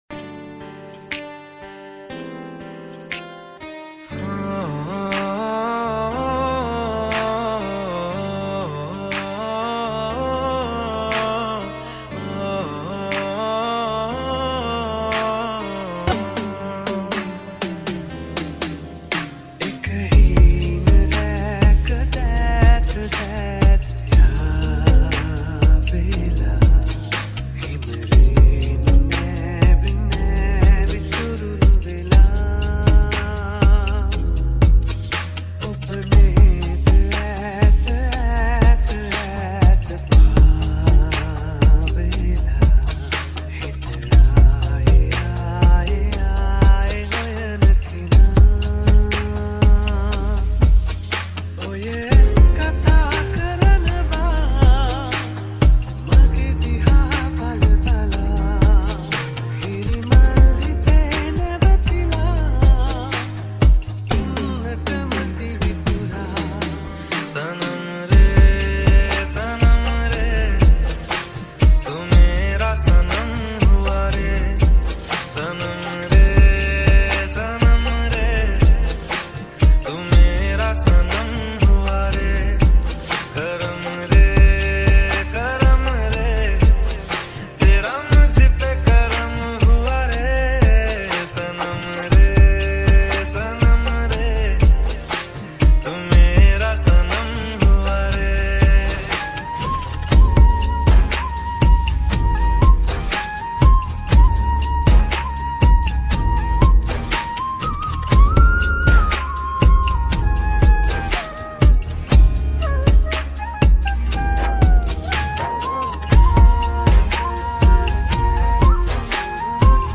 EDM Remix New Song